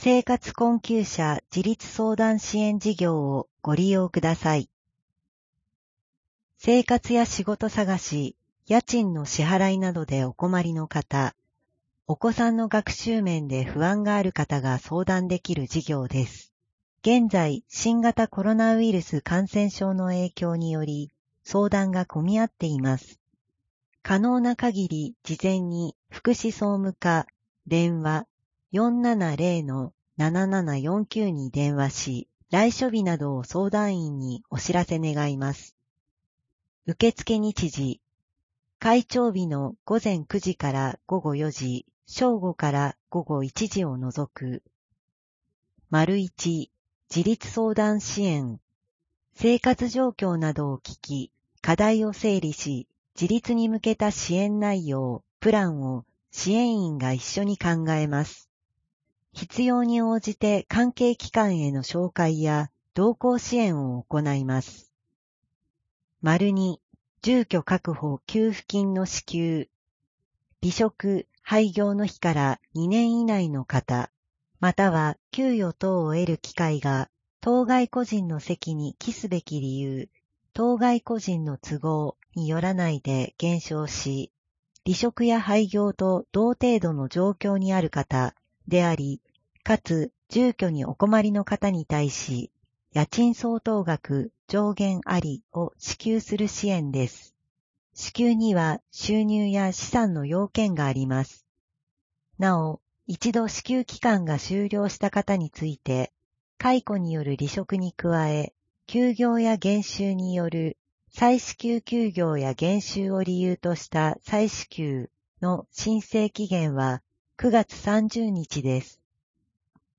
声の広報（令和3年7月1日号）